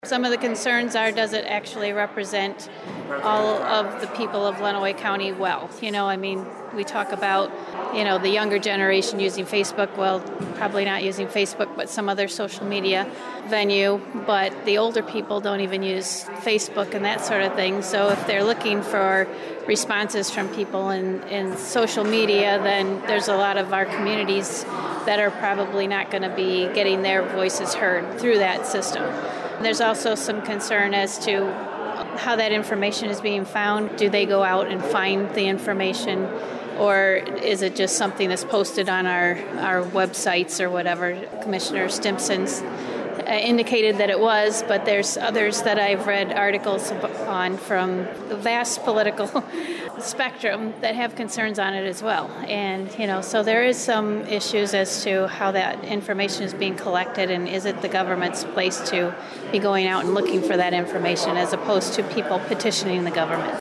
Commissioner Nancy Jenkins-Arno talked to WLEN News about the other concerns raised by the IT/Equalization Committee…